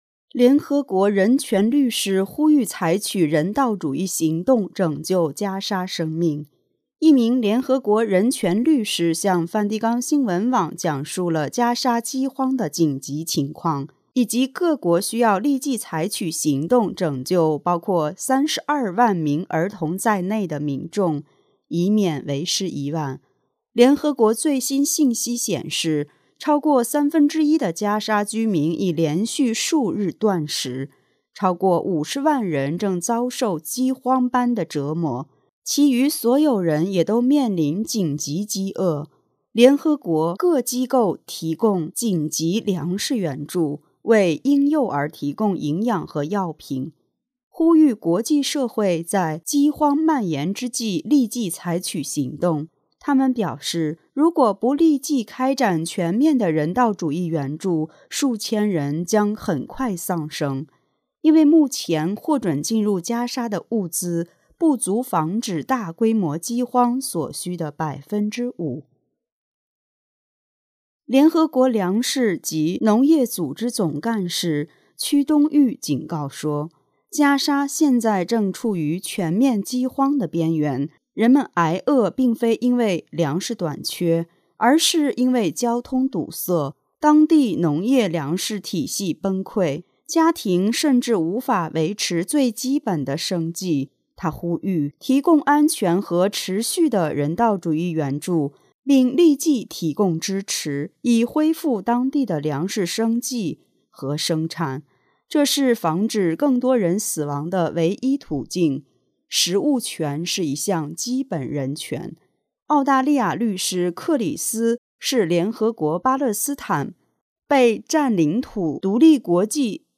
一名联合国人权律师讲述了加沙饥荒的紧急情况，以及各国需要立即采取行动拯救包括 32 万名儿童在内的民众，以免为时已晚。